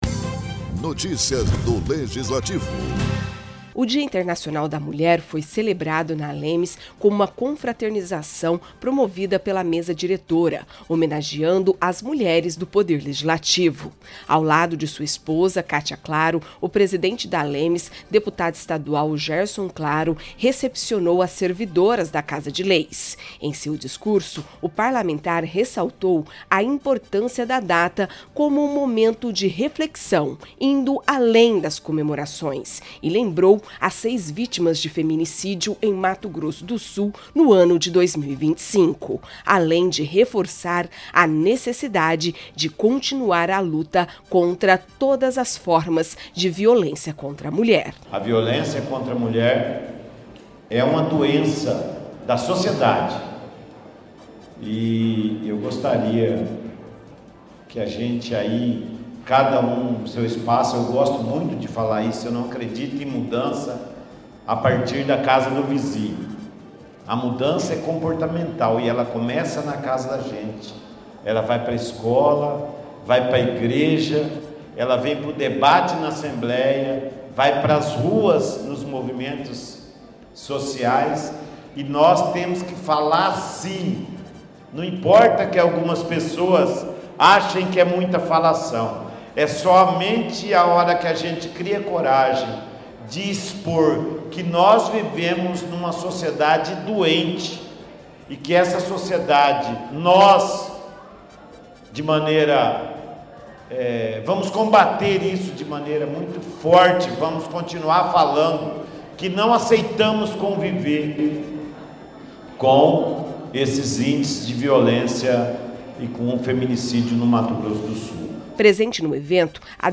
A ALEMS celebrou o Dia Internacional da Mulher com uma confraternização em homenagem às servidoras. O presidente, deputado Gerson Claro (PP), reforçou a data como um momento de reflexão sobre a luta contra a violência feminina, lembrando as seis vítimas de feminicídio em 2025.